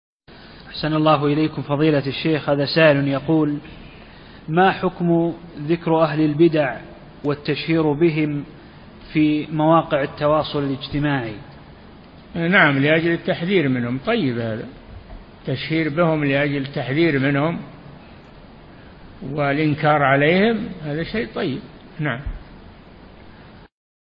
Album: موقع النهج الواضح Length: 0:24 minutes (172.5 KB) Format: MP3 Mono 22kHz 32Kbps (VBR)